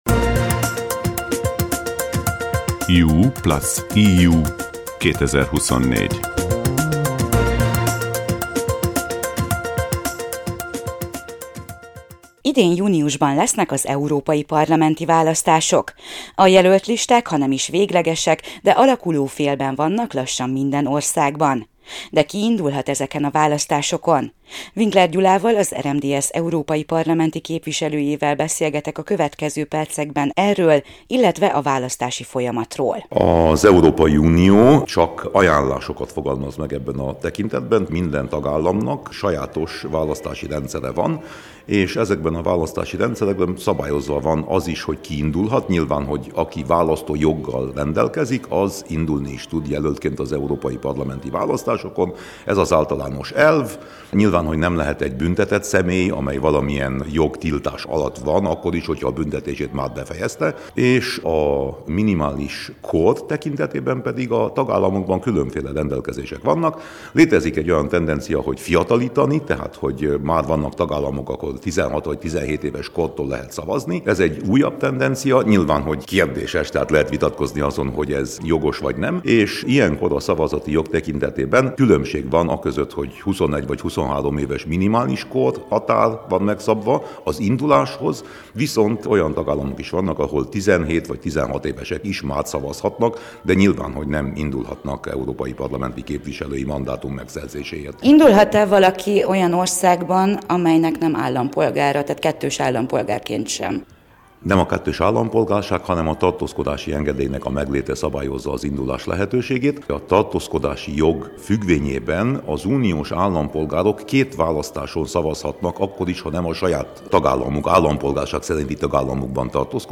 A Román Rádiótársaság podcast-sorozatot indított YouPlusEU 2024 címmel, az Európai Parlament támogatásával. Ebben a részben arról beszélgetünk Winkler Gyula EP-képviselővel, hogy ki indulhat, illetve ki szavazhat a választásokon, számít-e a kettős állampolgárság, és hogyan szavazzunk, ha épp nem vagyunk otthon.